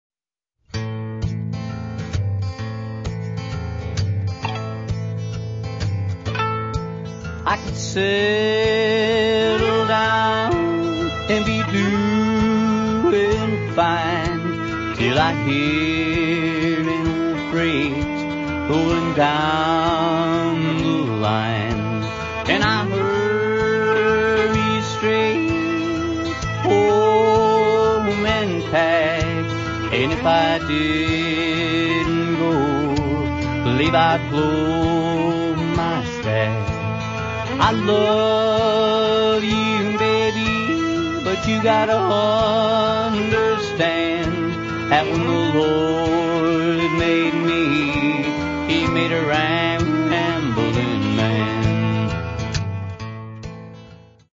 Old-Time Songs & Longbow Fiddle
guitar & lead vocals
fiddle